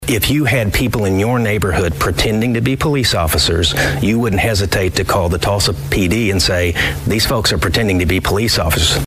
That dispute has now turned public, as Cherokee Nation Chief Chuck Hoskin Jr. says the UKB is a threat to Cherokee sovereignty and public safety as a whole.
Here is what he had to tell the News on 6 earlier this week.